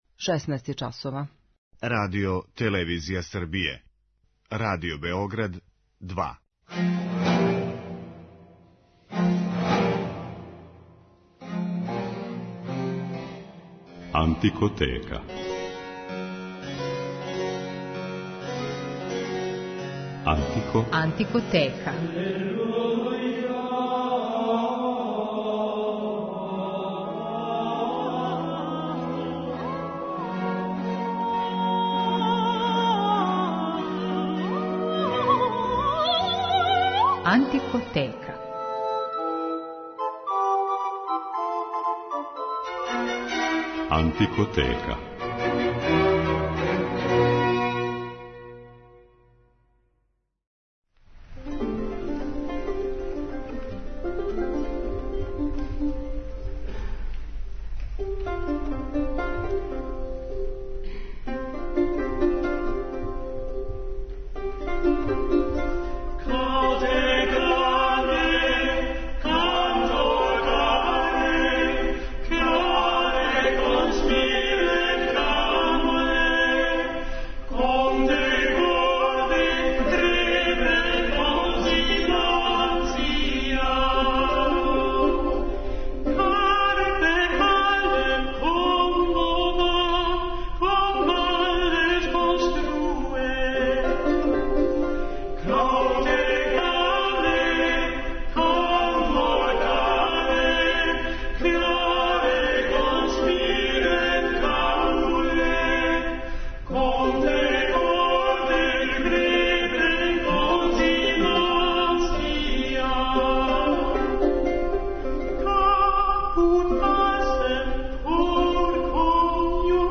Световне песме средњег века